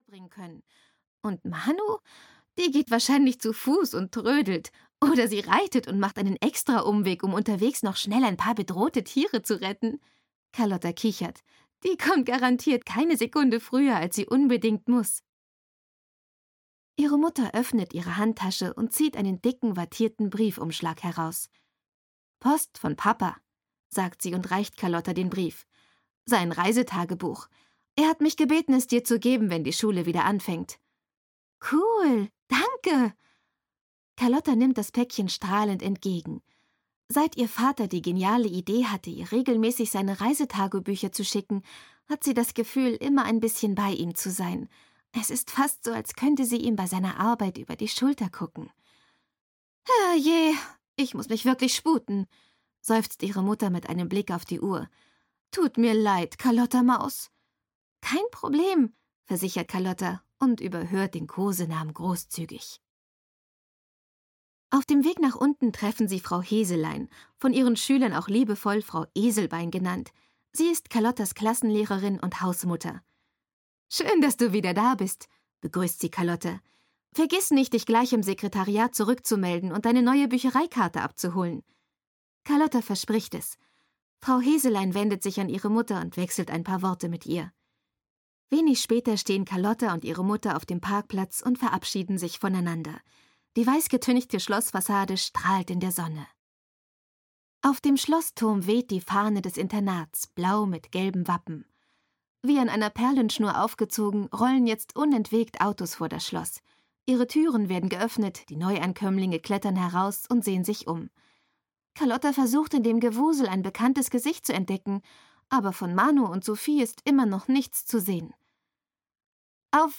Carlotta 2: Carlotta - Internat und plötzlich Freundinnen - Dagmar Hoßfeld - Hörbuch